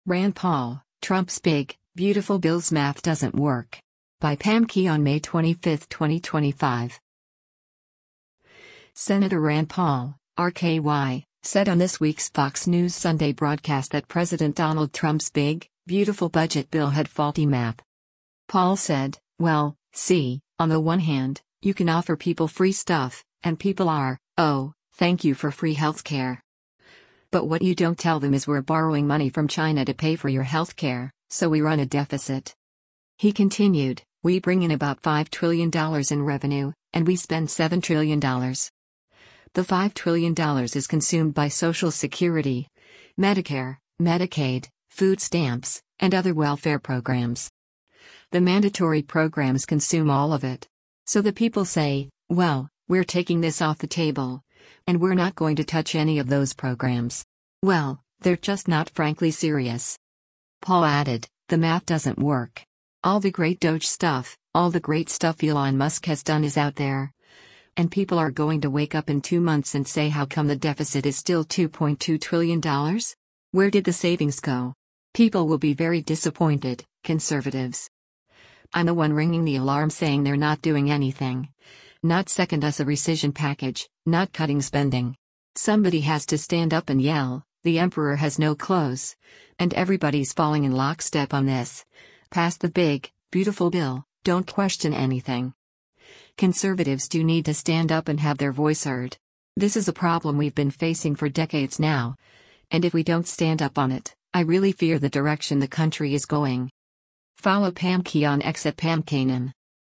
Senator Rand Paul (R-KY) said on this week’s “Fox News Sunday” broadcast that President Donald Trump’s big, beautiful budget bill had faulty math.